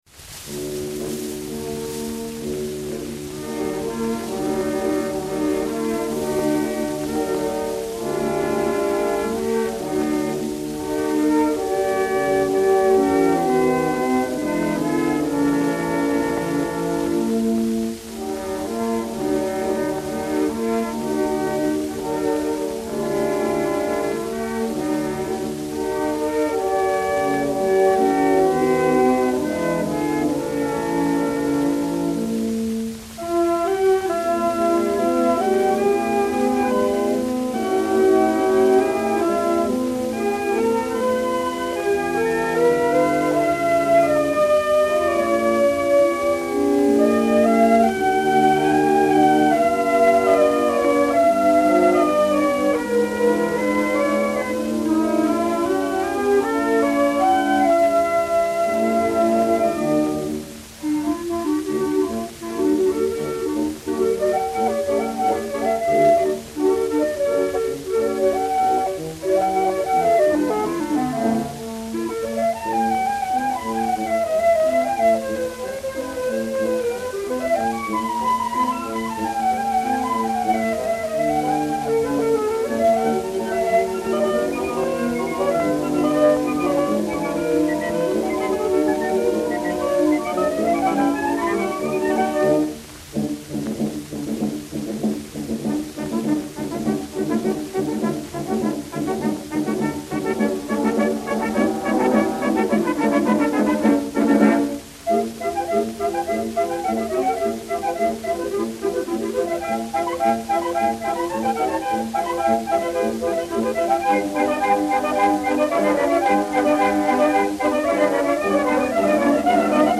Suite d'orchestre des Deux Pigeons
Musique de la Garde Républicaine